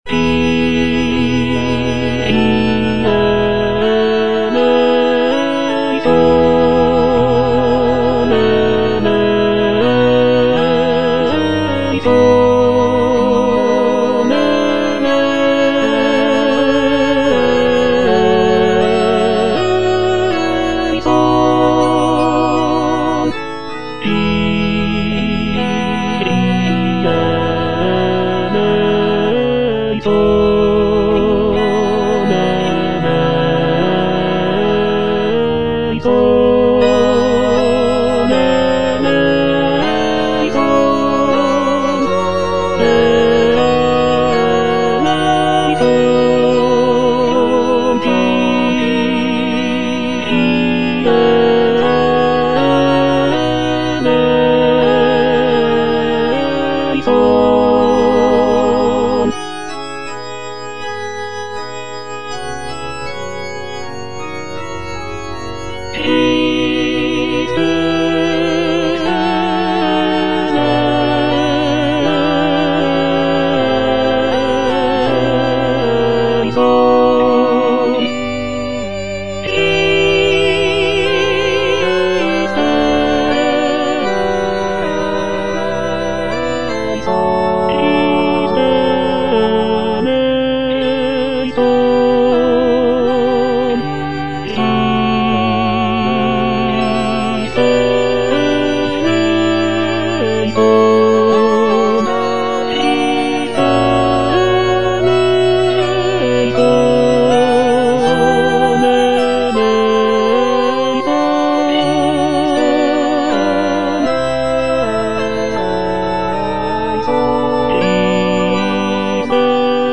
J.G. RHEINBERGER - MISSA MISERICORDIAS DOMINI OP.192 Kyrie - Tenor (Emphasised voice and other voices) Ads stop: auto-stop